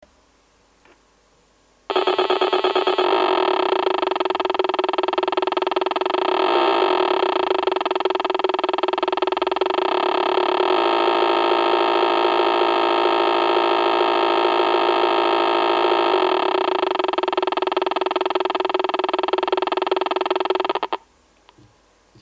Diese Modell-Ente besitzt einen Elektro-Antrieb, kann vorwärts und rückwärts schwimmen und dabei realistische Geräusche eines Dieselmotors abgeben.
Anschließend lässt sie den Motor noch einige Zeit im Leerlauf laufen und schaltet ihn dann ab, bis erneut jemand am Teich vorbeikommt...
Die Ente besitzt übrigens keinen DA-Wandler zur Soundausgabe! Es wird lediglich der Lausprecher in kurzen Abständen aus und eingeschaltet.
entenmotor.mp3